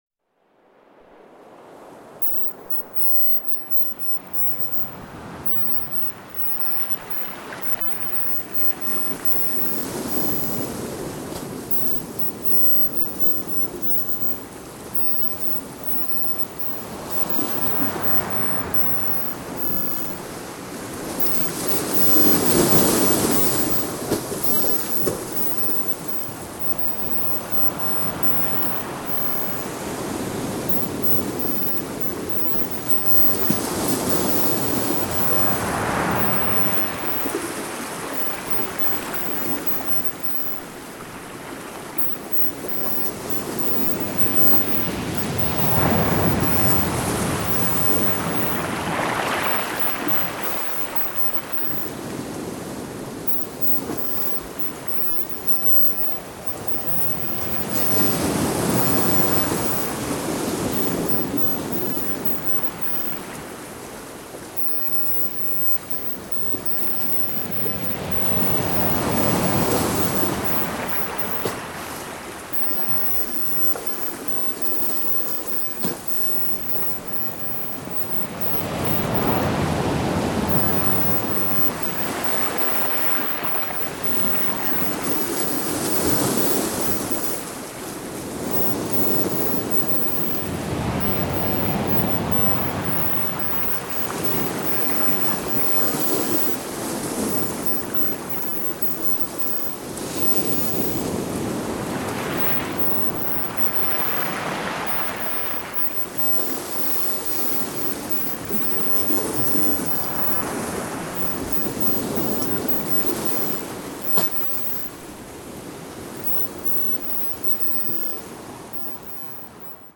No Audio Induction; No Audio Affirmations;
No Music Ambience; Nature Ambience
This Subliminal Wealth affirmation program contains nature sounds (sea) under which a subliminal inaudible voice reiterates special affirmations to help you attract money and wealth into your life.
wealth_sea_affirmations_sublim_lq.mp3